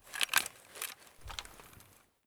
draw_shotgun.ogg